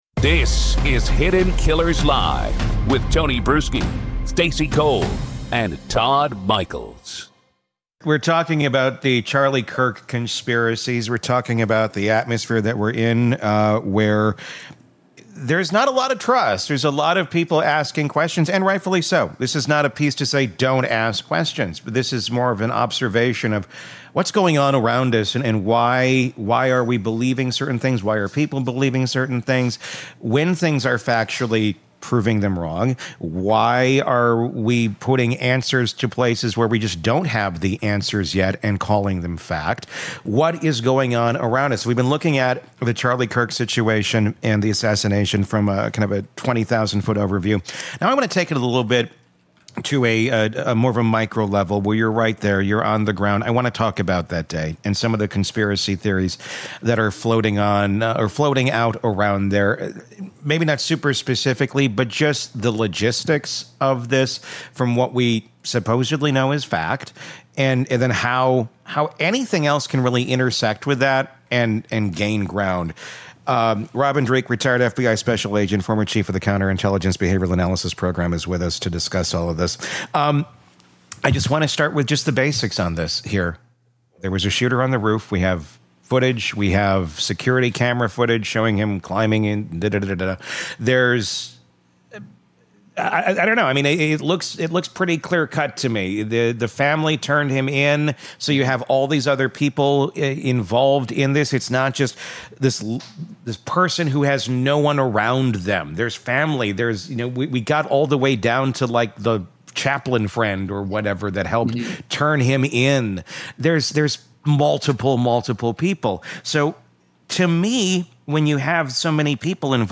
behavioral analyst and former FBI agent